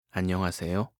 알림음 8_안녕하세요4-남자.mp3